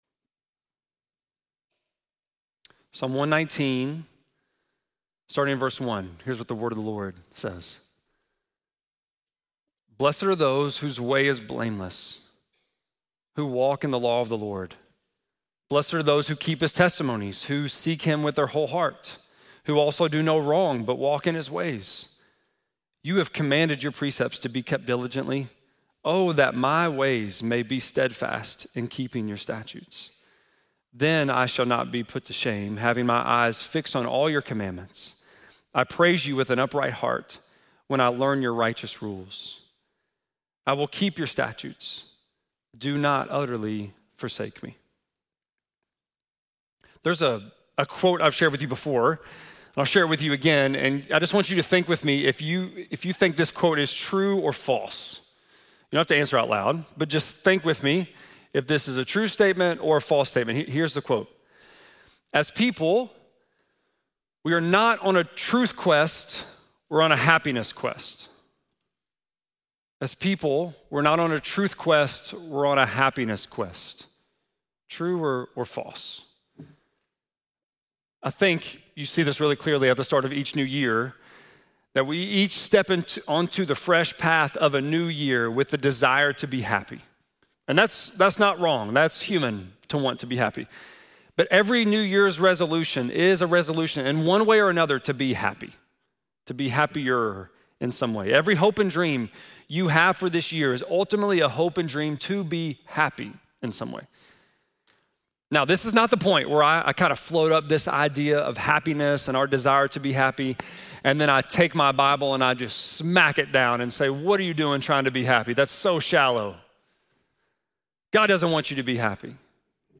1.4-sermon.mp3